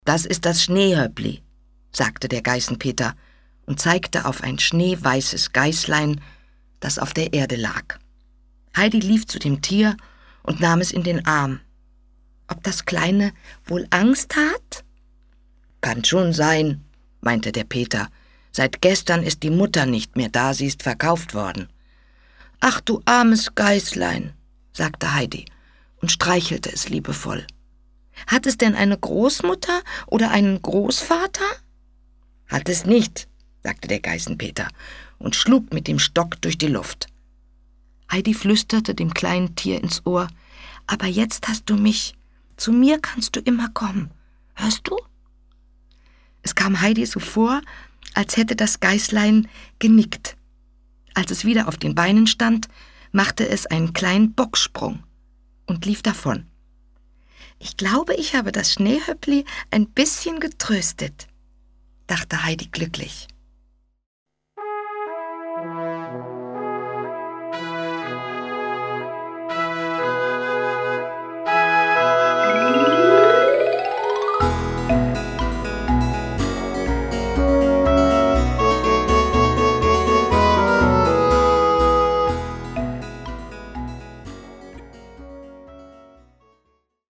Die klassischen Texte wurden behutsam gekürzt und liebevoll nacherzählt.